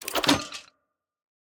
Minecraft Version Minecraft Version 25w18a Latest Release | Latest Snapshot 25w18a / assets / minecraft / sounds / block / vault / reject_rewarded_player.ogg Compare With Compare With Latest Release | Latest Snapshot